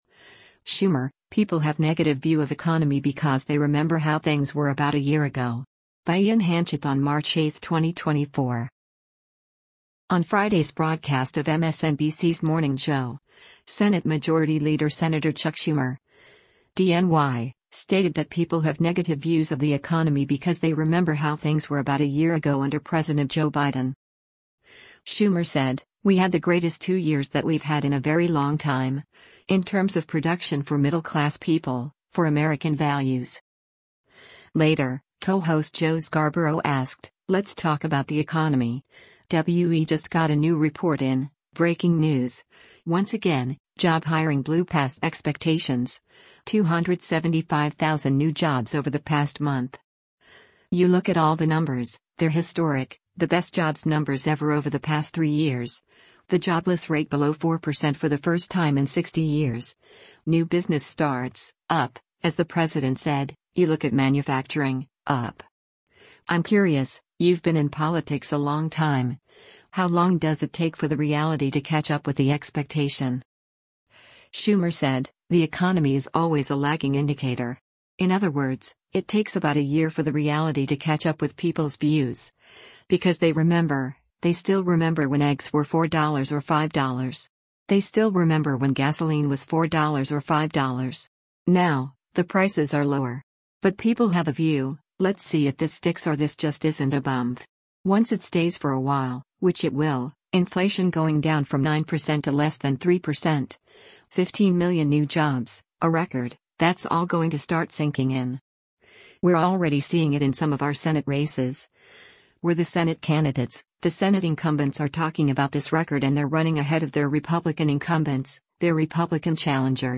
On Friday’s broadcast of MSNBC’s “Morning Joe,” Senate Majority Leader Sen. Chuck Schumer (D-NY) stated that people have negative views of the economy “because they remember” how things were about a year ago under President Joe Biden.